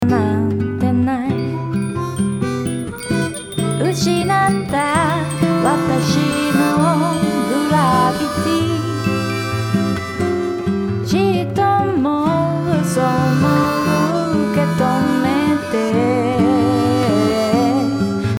今回新しく追加されたパーカッション、グロッケンを少しだけ横に広げてみました。
グロッケンの音にはディレイをかけて余韻を持たせてみました。
ちょっと立体になって華やかさも少しだけ足されていい感じになった気がします。
惜しむ点はボーカルの音をもう少し前に出せたらよかったかなぁ…。音量的に目立たせるのではなく、前に…。